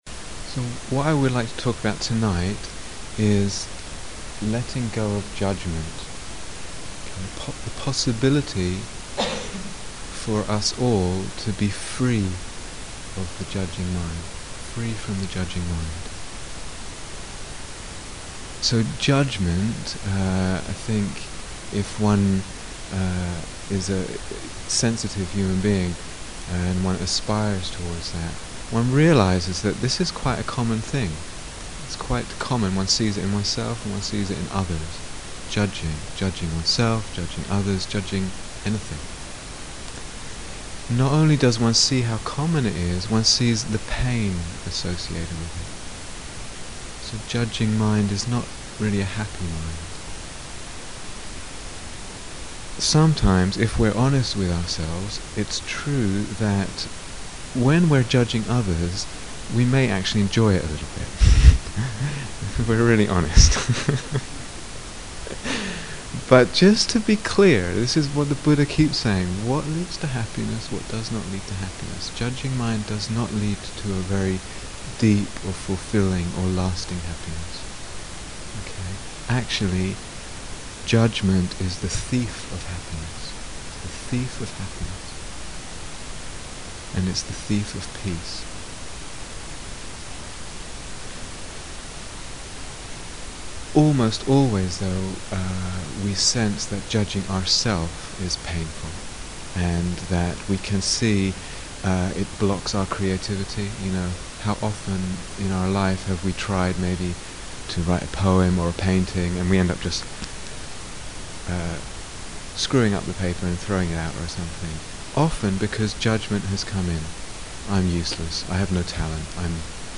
Talk Given Between Retreats